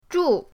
zhu4.mp3